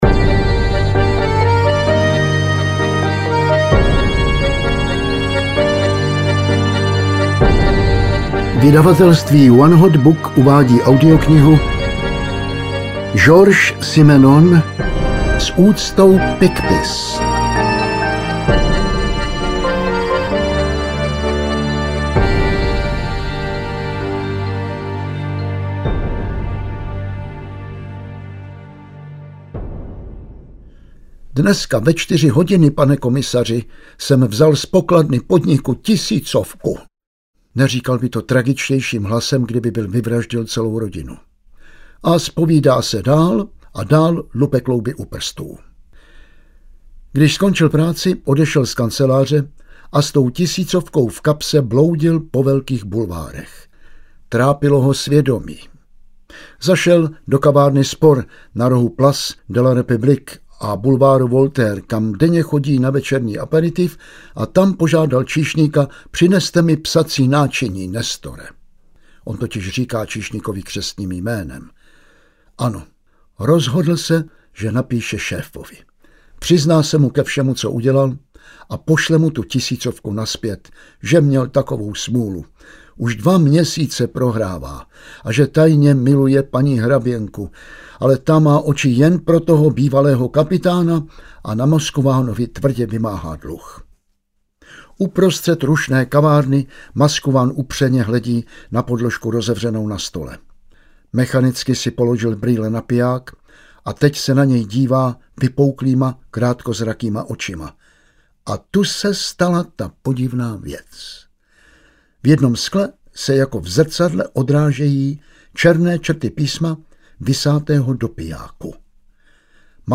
Maigret - S úctou Picpus audiokniha
Ukázka z knihy
• InterpretJan Vlasák